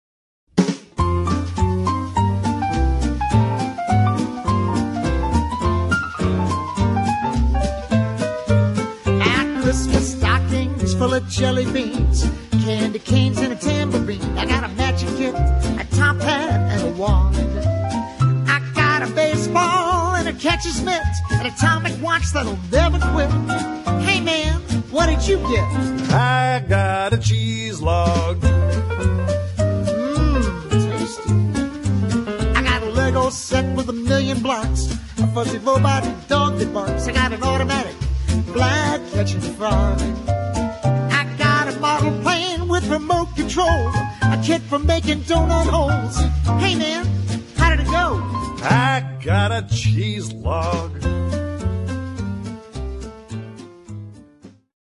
--funny Christmas music